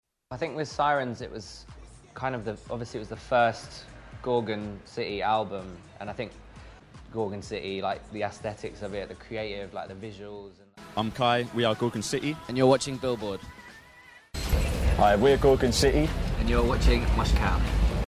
インタビューより本人からの自己紹介